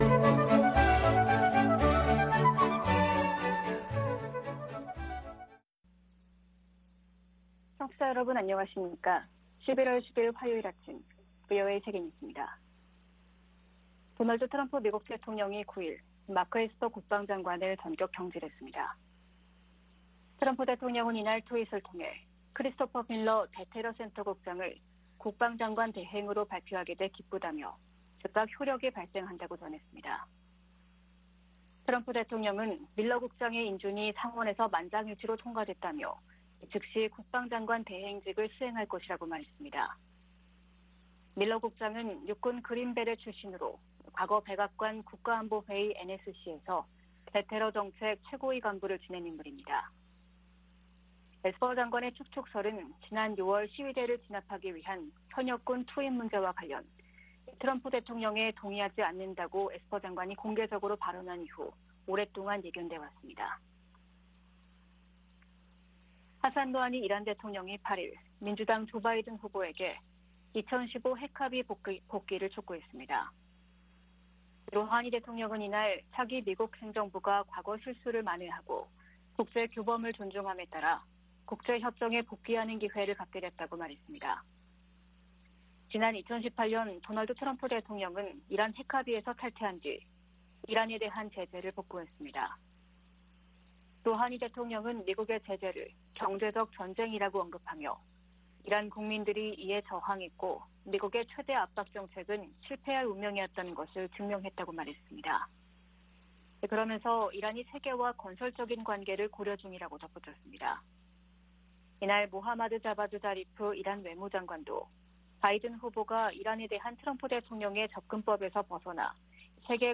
VOA 한국어 아침 뉴스 프로그램 '워싱턴 뉴스 광장'입니다.